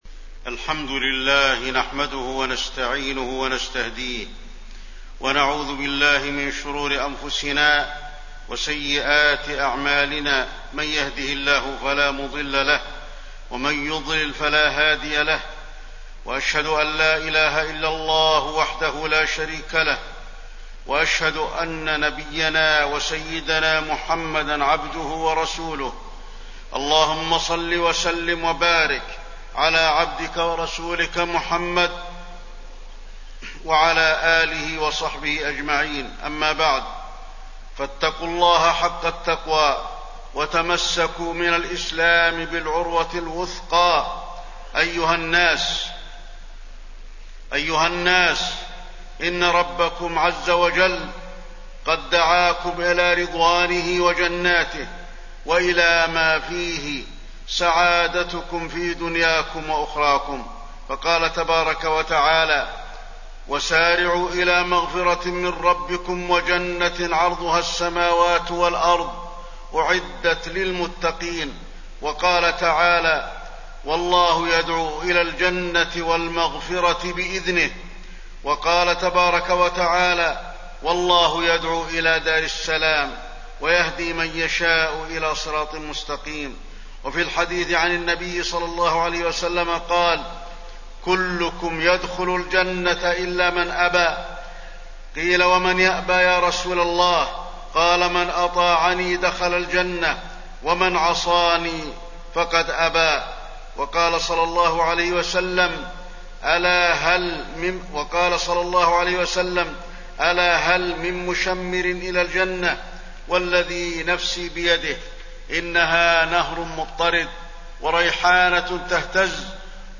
تاريخ النشر ٢١ صفر ١٤٣١ هـ المكان: المسجد النبوي الشيخ: فضيلة الشيخ د. علي بن عبدالرحمن الحذيفي فضيلة الشيخ د. علي بن عبدالرحمن الحذيفي وجوب اتباع سنة النبي صلى الله عليه وسلم